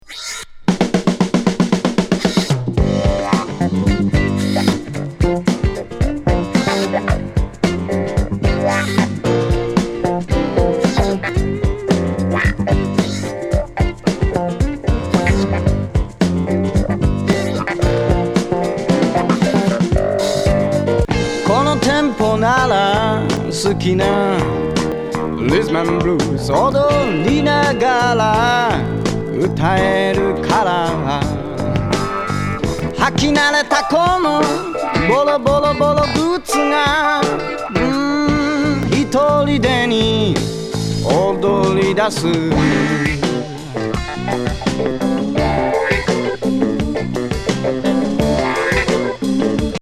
和ブルージー・ファンク
ファンキー・グルーヴ